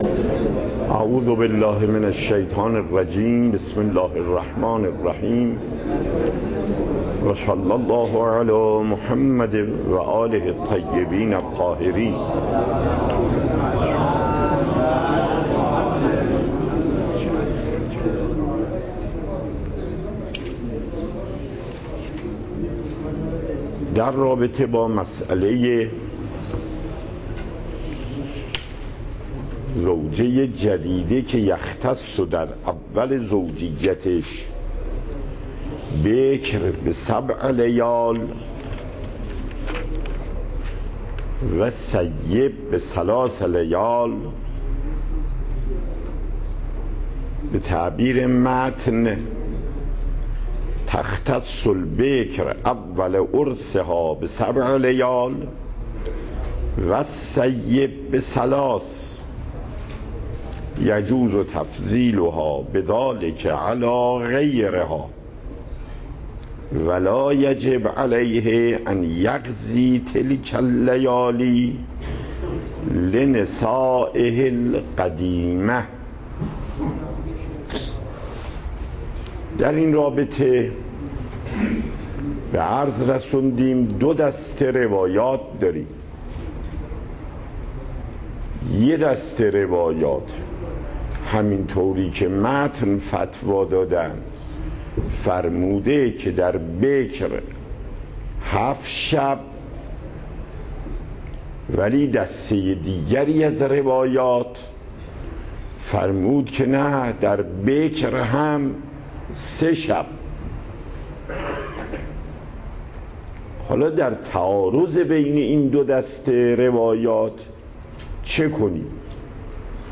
پخش زنده صوت درس + دریافت صوت و تقریر درس